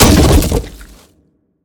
biter-death-big-5.ogg